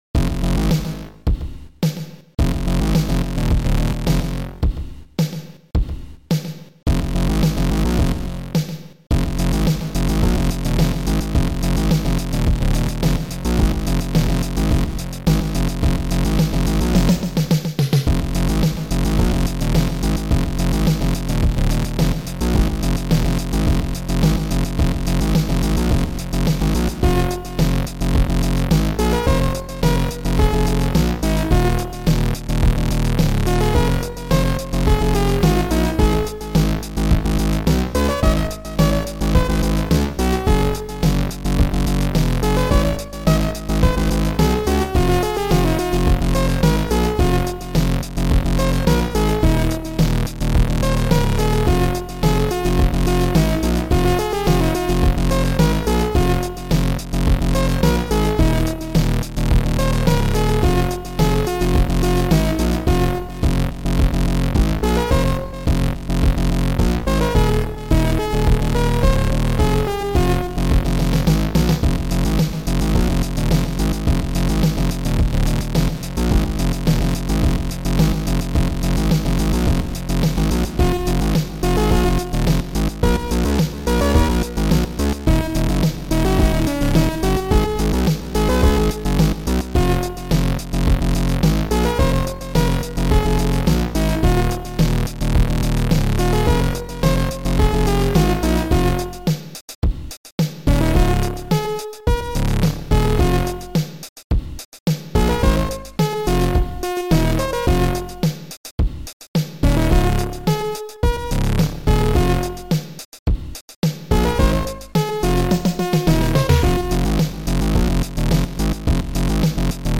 Sound Format: Noisetracker/Protracker
Sound Style: Synth Effect